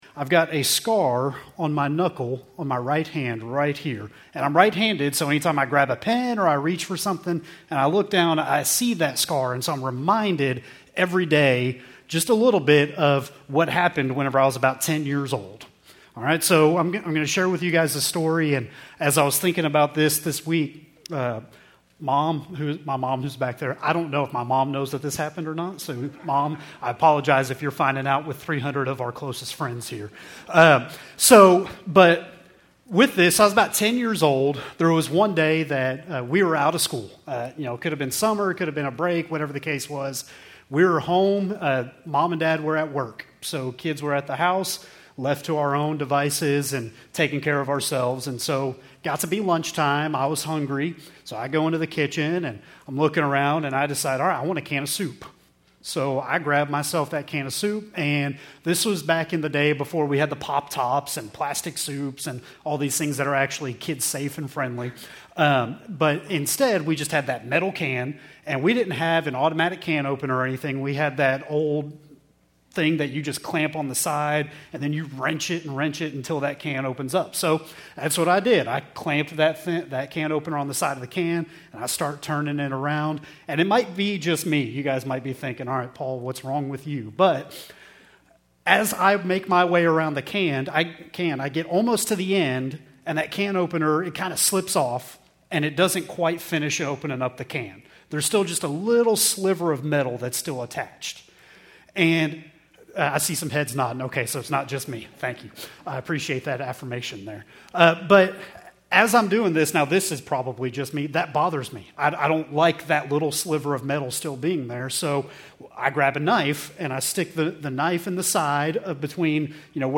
Keltys Worship Service, December 8, 2024